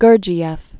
(gûrjē-ĕf, -jĭf), George Ivanovich 1874?-1949.